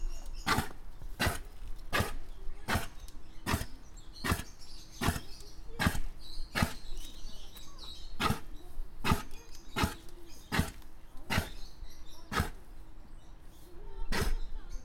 Здесь собраны натуральные записи: от мягкого перестука копыт до мощного рёва во время гона.
Голос самки северного оленя (Rangifer tarandus)